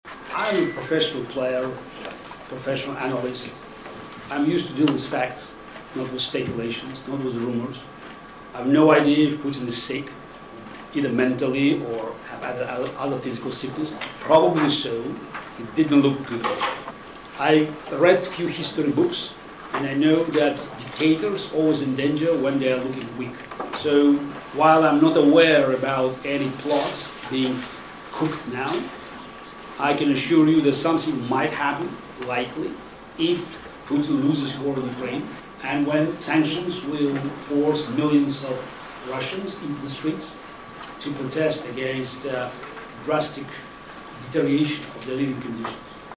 Garry Kasparov, campion mondial la șah și oponent al regimului Putin, spune la București, într-o conferință de presă, că Vladimir Putin va sfârși la fel ca dictatorii care au activat politic înaintea lui. Șahistul mai spune că întreaga carieră politică a lui Putin depinde doar de rezultatele războiului din Ucraina.